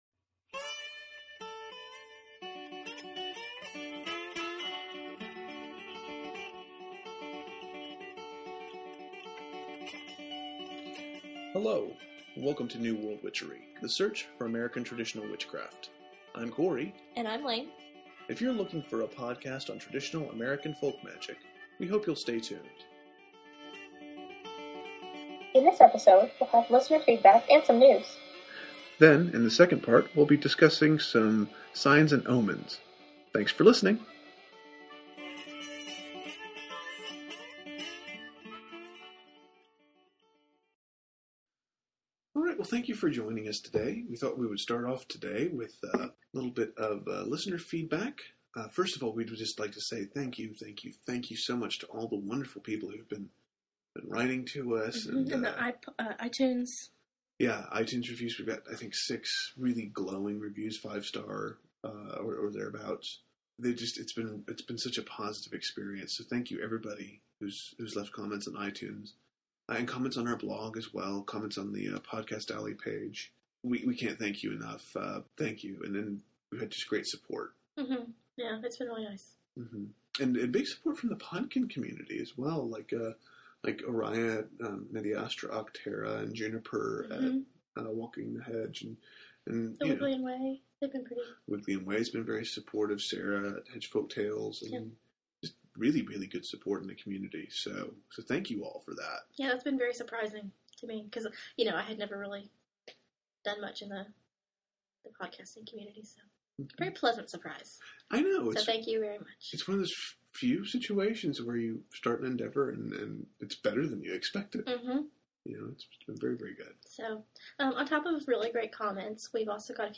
Promo 1- The Standing Stone and Garden Gate Podshow Promo 2- Media Astra ac Terra Promo 3- A Pagan in the Threshold Promo 4 – Inciting a Riot Podcast P.S. This is just a quick apology for the lateness of this episode, as well as occasional shifts in sound quality. We have been having trouble with our podcast recording software, and had to re-record parts of our show via Skype.